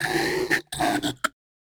Techno / Voice / VOICEFX186_TEKNO_140_X_SC2.wav